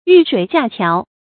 遇水架橋 注音： ㄧㄩˋ ㄕㄨㄟˇ ㄐㄧㄚˋ ㄑㄧㄠˊ 讀音讀法： 意思解釋： 見「遇水迭橋」。